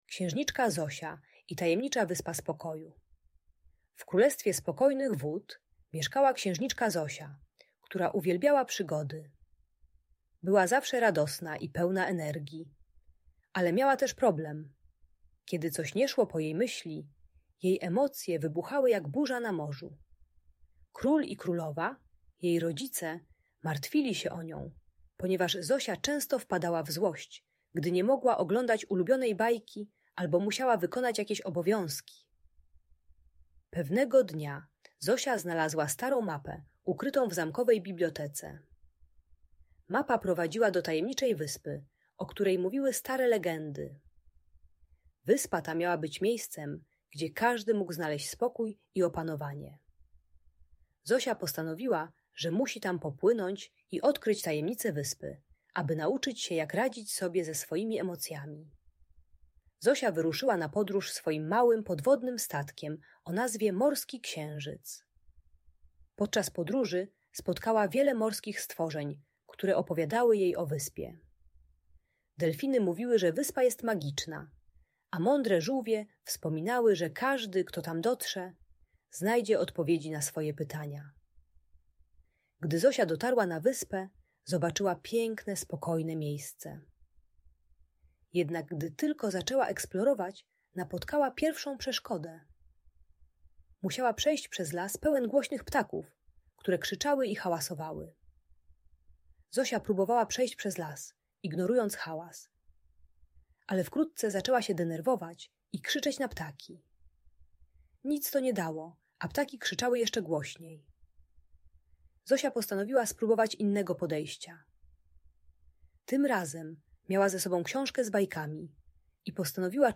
Księżniczka Zosia uczy techniki głębokiego oddychania, liczenia do dziesięciu i wizualizacji spokojnego miejsca. Audiobajka o złości i agresji dla przedszkolaka, który krzyczy gdy coś nie idzie po jego myśli.